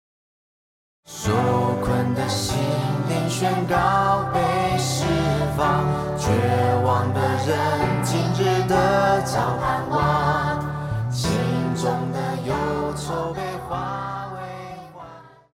Christian
Pop chorus,Children Voice
Band
Christmas Carols,Hymn,POP,Christian Music
Voice with accompaniment